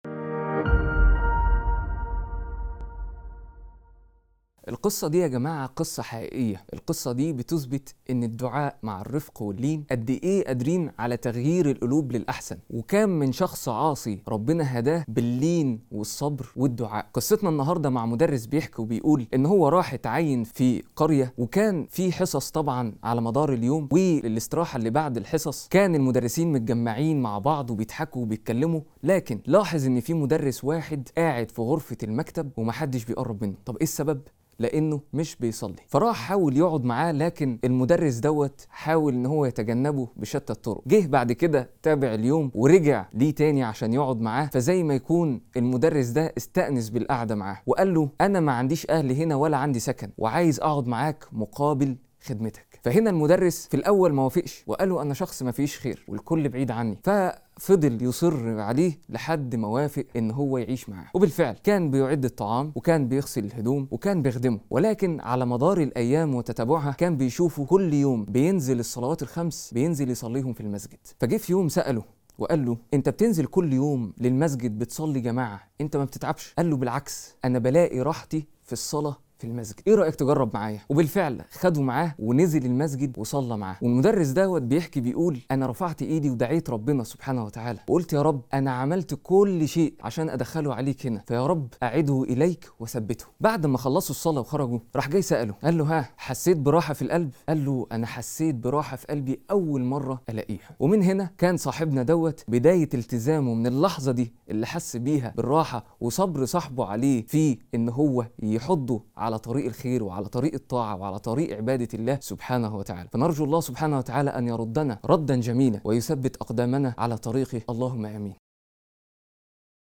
قصة وعظية مؤثرة عن رجل عاصٍ اهتدى إلى طريق الله بفضل الدعاء والصحبة الصالحة والصبر. تبرز القصة كيف يكون الدعاء باللين سببًا في تغيير القلوب، وتجسد معنى الأنس بالله والراحة الحقيقية في الطاعة والصلاة.